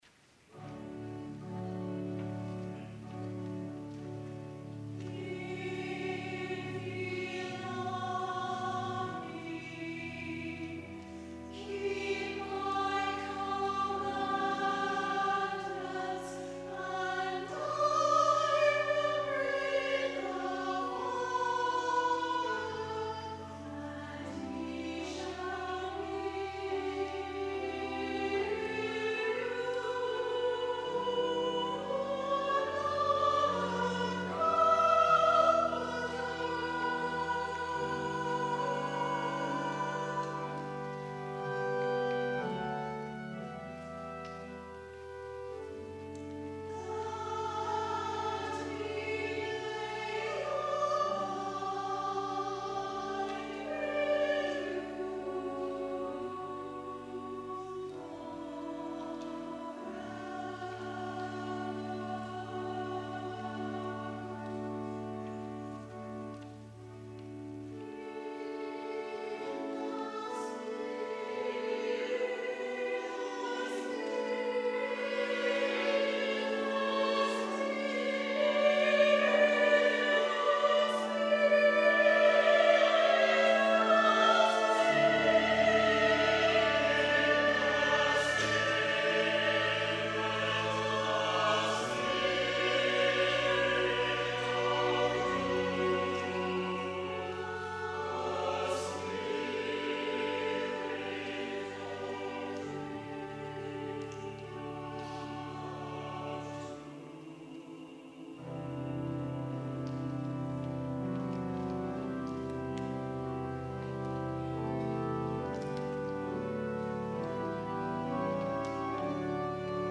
Chancel Choir
organ